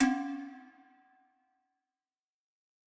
error.ogg